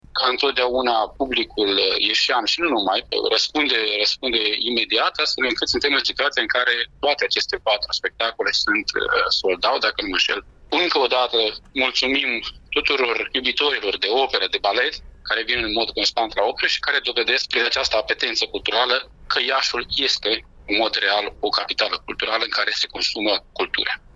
Într-o declarație pentru postul nostru de radio